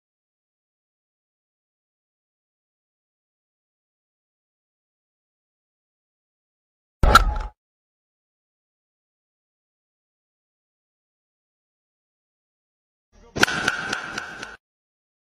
𝐒𝐢𝐱 𝐒𝐨𝐮𝐧𝐝 𝐟𝐨𝐫 𝐂𝐫𝐢𝐜𝐤𝐞𝐭 𝐞𝐝𝐢𝐭𝐨𝐫 sound effects free download